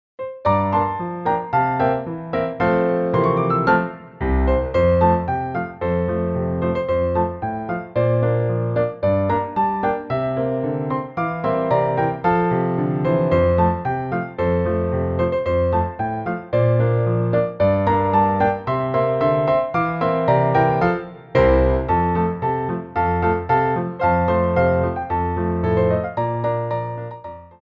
Frappés
2/4 (16x8)